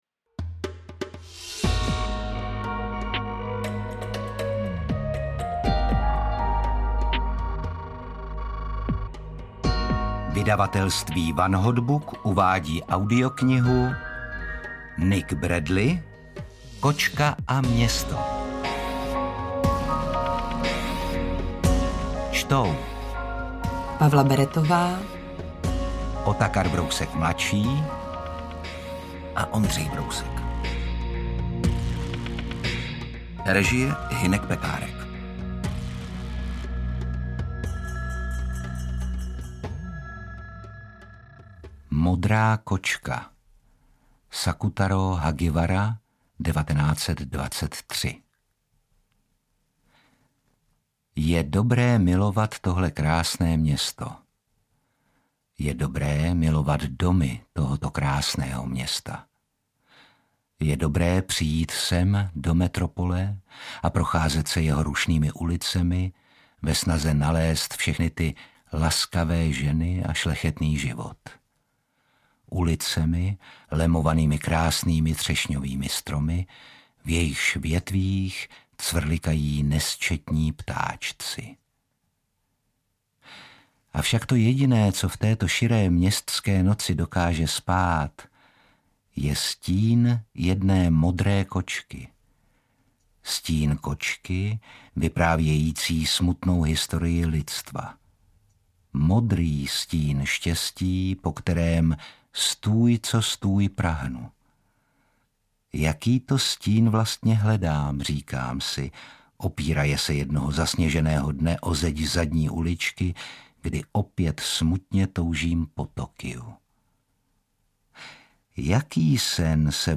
Interpreti:  Pavla Beretová, Otakar Brousek ml., Ondřej Brousek
AudioKniha ke stažení, 32 x mp3, délka 10 hod. 16 min., velikost 555,0 MB, česky